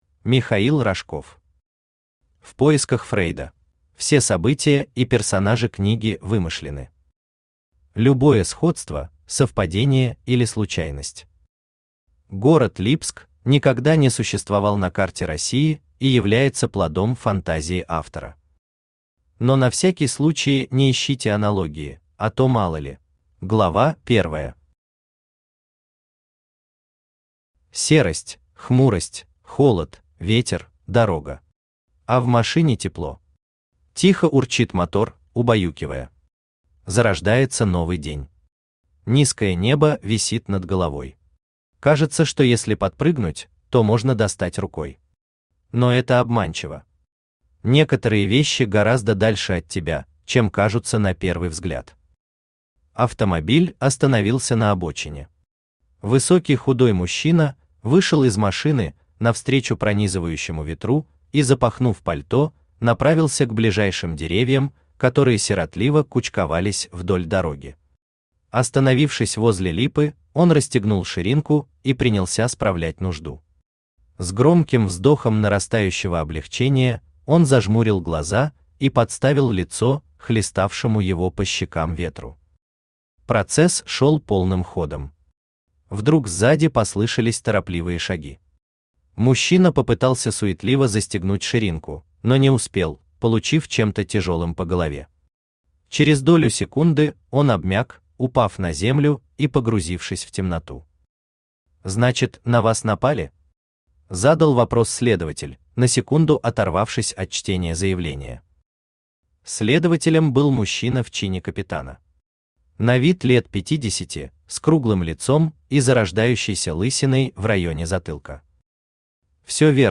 Аудиокнига В поисках Фрейда | Библиотека аудиокниг
Aудиокнига В поисках Фрейда Автор Михаил Павлович Рожков Читает аудиокнигу Авточтец ЛитРес.